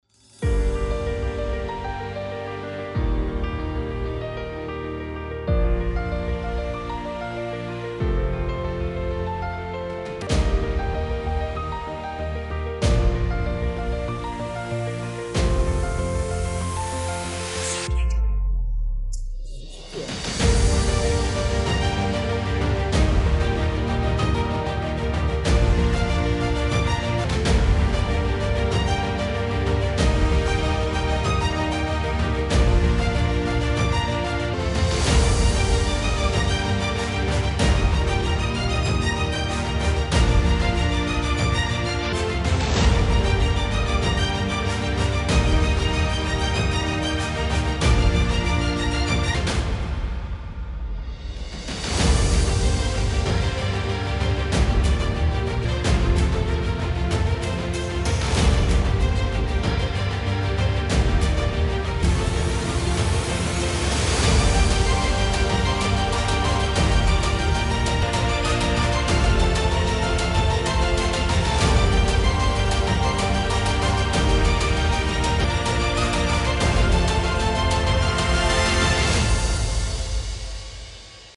大气磅礴